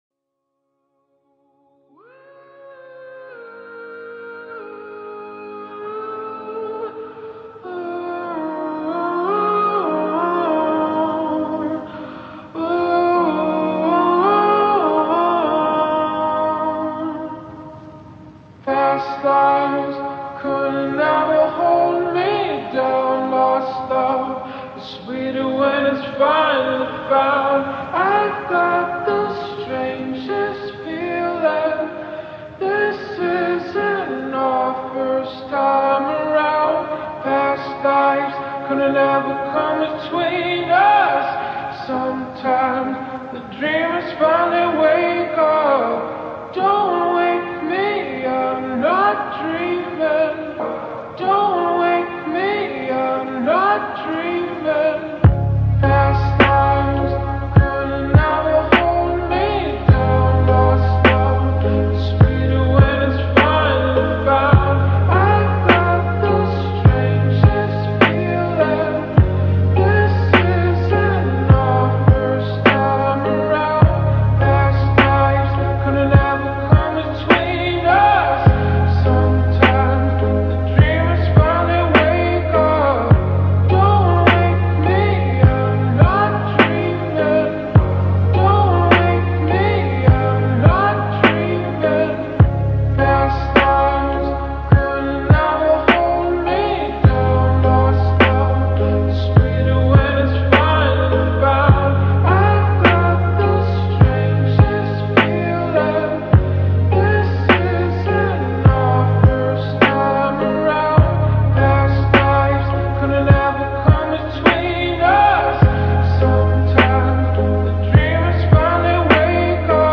ورژن اسلو موزیک
slowed version